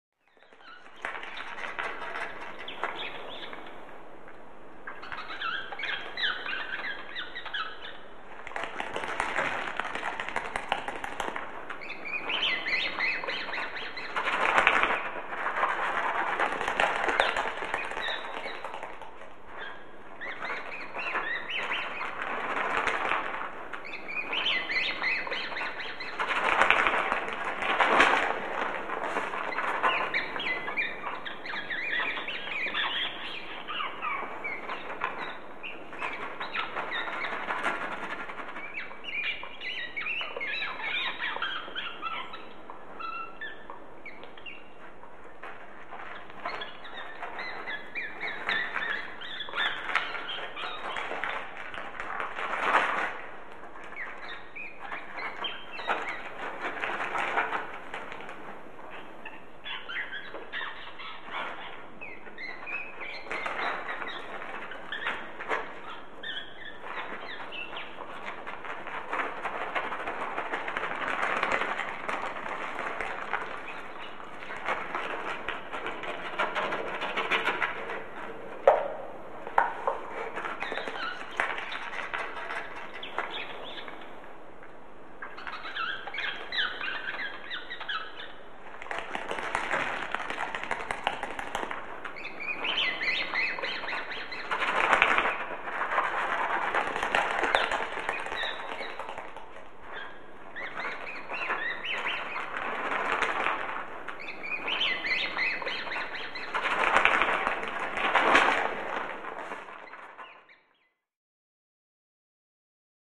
Rats: Multiple Squeaks And Movement.